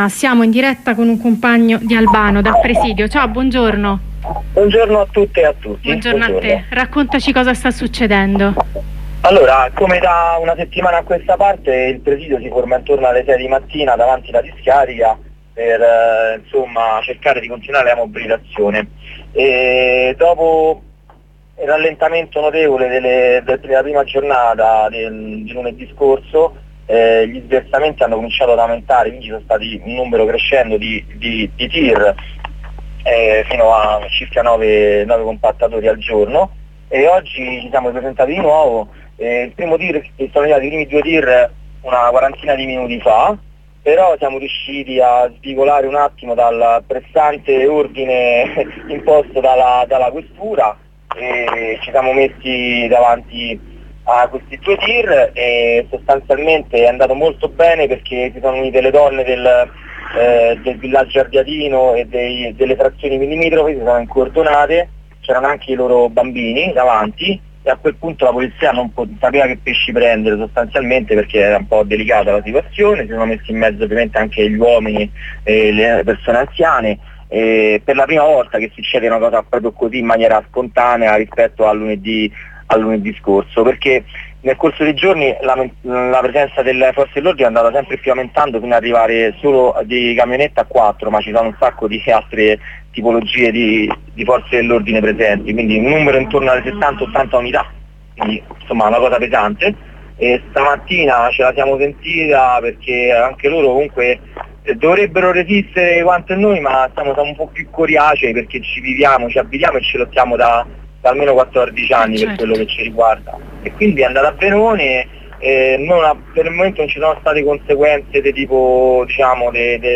Intervento telefonico